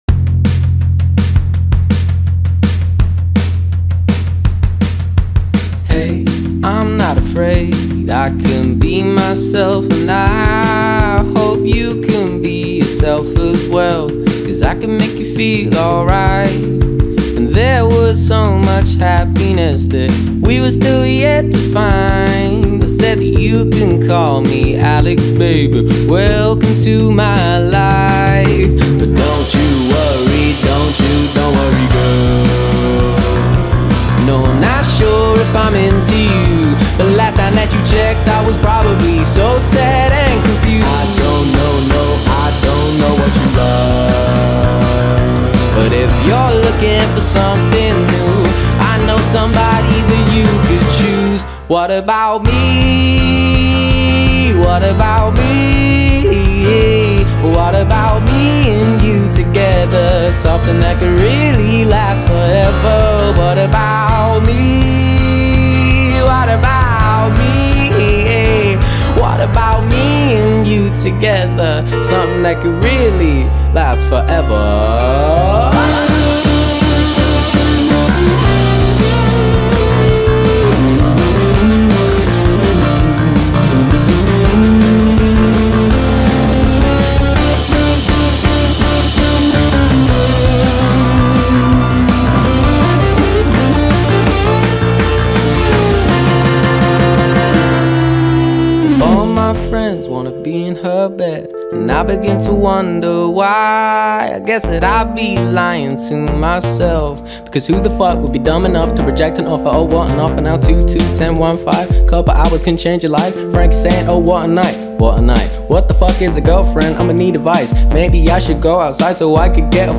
غمگین و احساسی